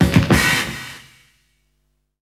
HF101BREAK-R.wav